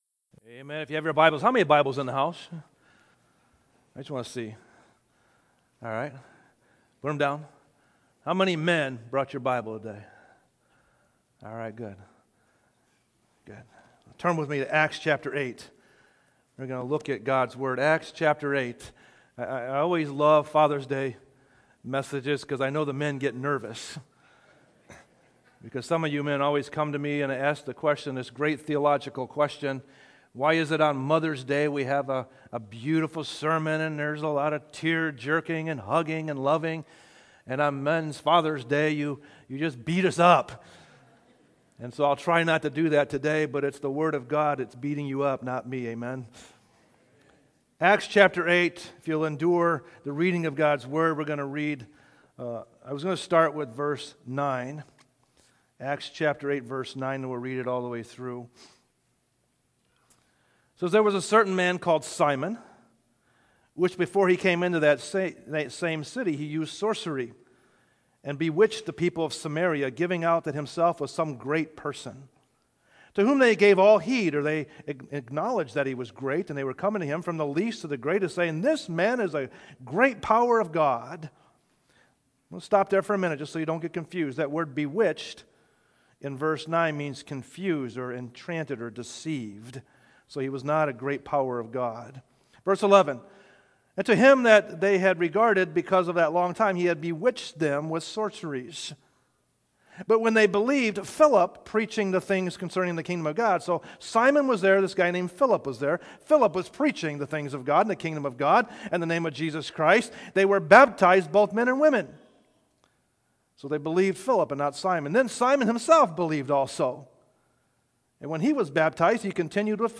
Sermons - Grace Baptist Church
sermons preached at Grace Baptist Church in Portage, IN